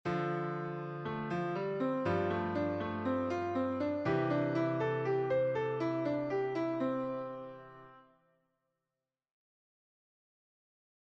Improvisation Piano Jazz
Utilisation des Pentatoniques sur un ii V I Majeur